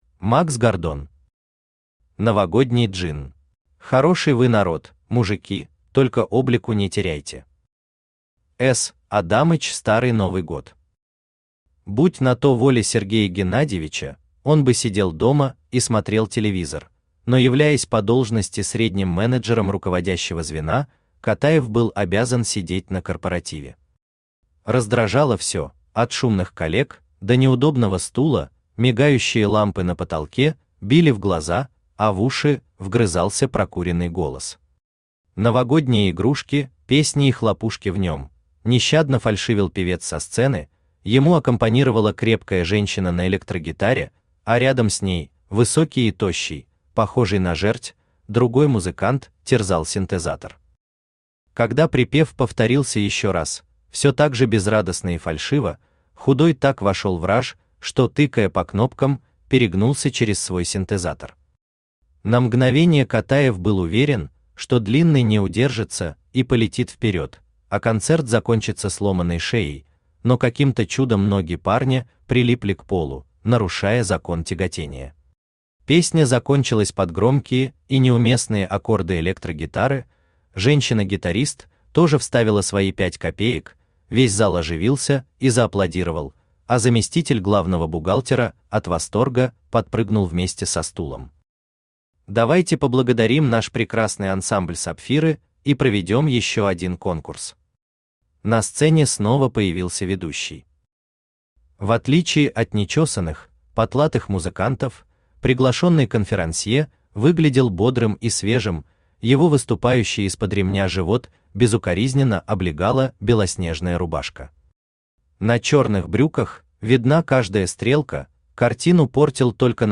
Аудиокнига Новогодний джинн | Библиотека аудиокниг
Aудиокнига Новогодний джинн Автор Макс Гордон Читает аудиокнигу Авточтец ЛитРес.